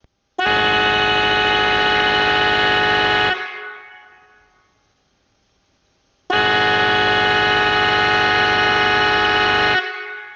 Fisa avertisseur Mariner 2 12V110dB54W420Hz high tone370Hz low toneAudibilité sur la mer : 1500m